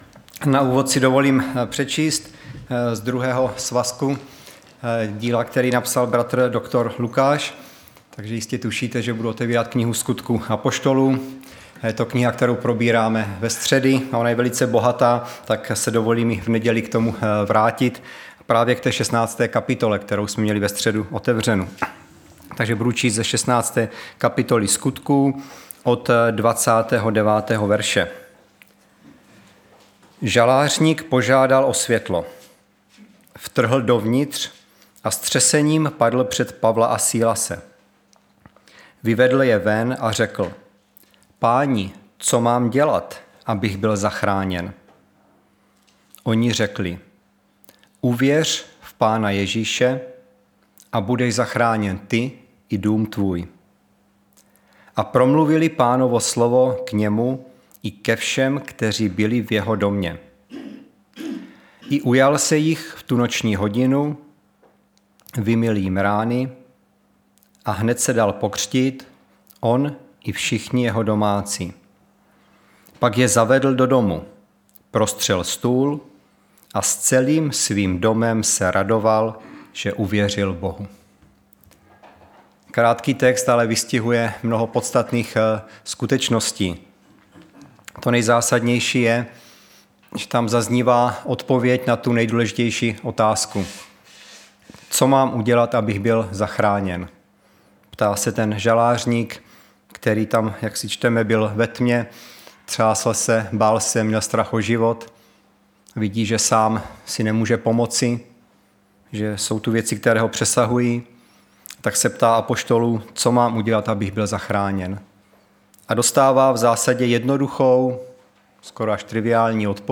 Nedělní vyučování
Záznamy z bohoslužeb